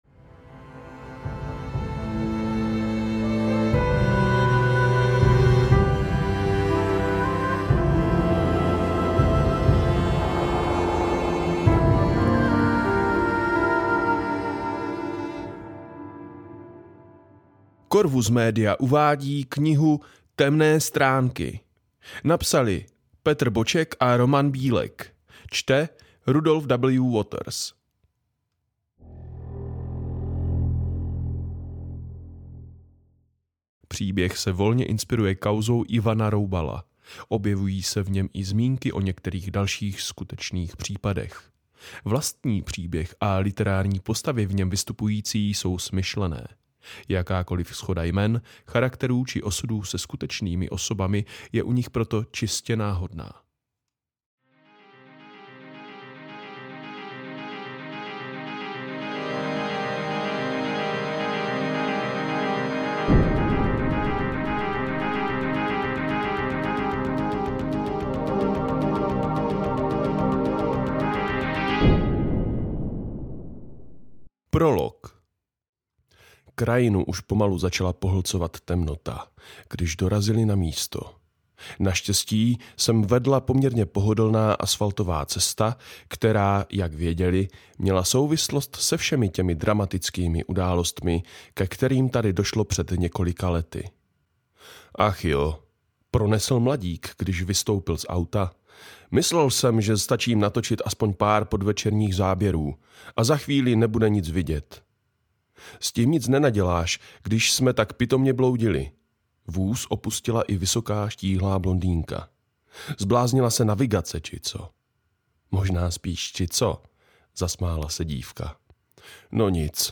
Temné stránky audiokniha
Ukázka z knihy
temne-stranky-audiokniha